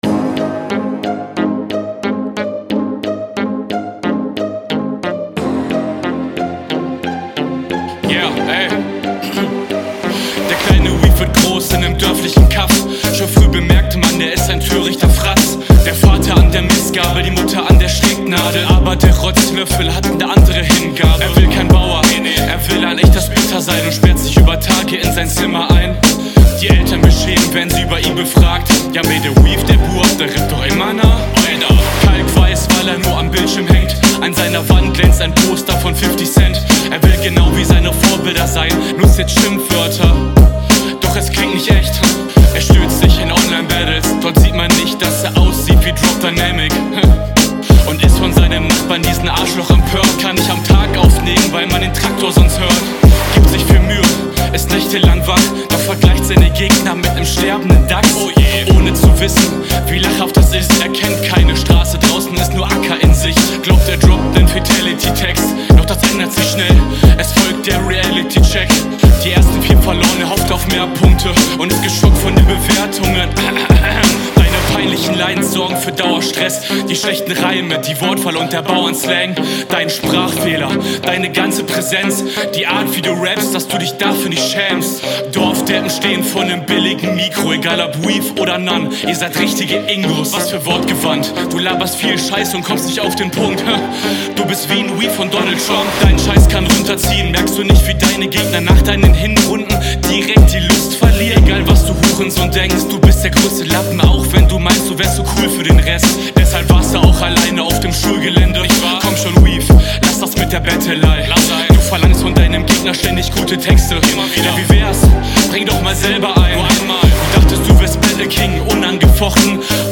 Flow wieder ein bisschen mid, liegt hauptsächlich am Tempo denke …
Vocals bisschen zu leise, musste an manchen stellen genauer hinhören oder konzentriert mithören was bisschen …